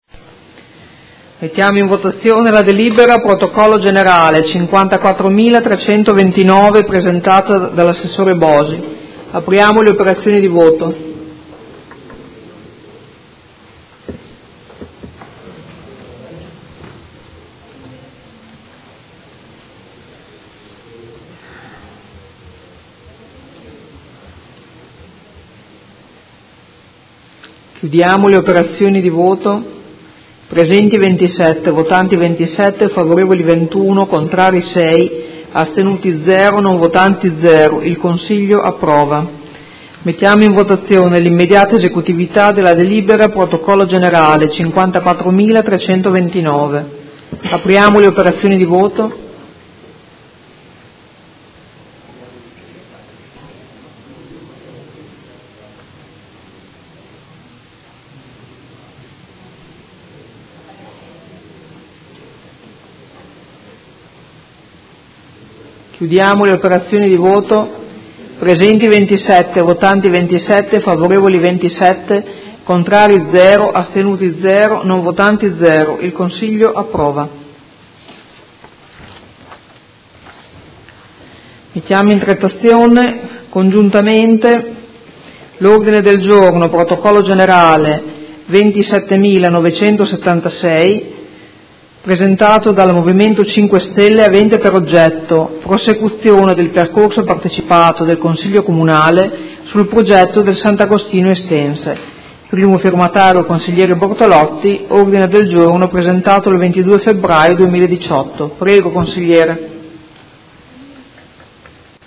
Audio Consiglio Comunale